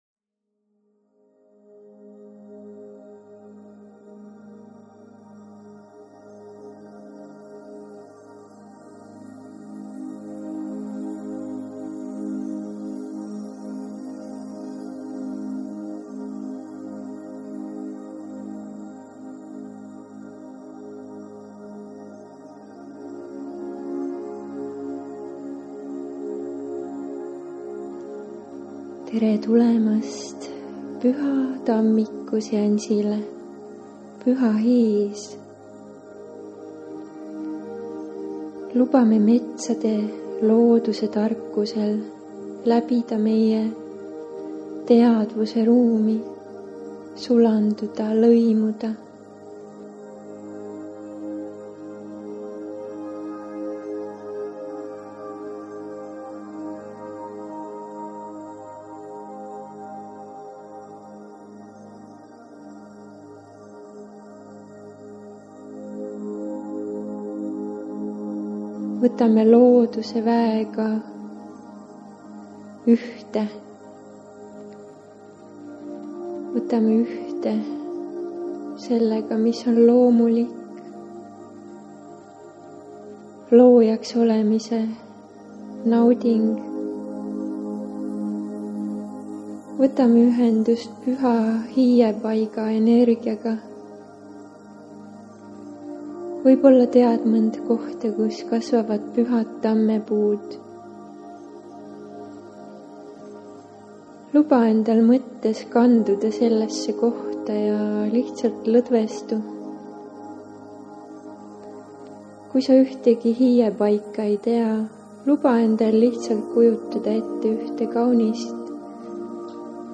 Seansi käigus vabastame vanad mittetoetavad mustrid ja suuname energia uuele ümber. See on lihtne, väga tõhus ja toimiv kaugreiki seanss.